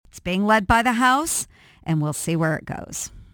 She commented briefly Wednesday during a conference call with Iowa reporters.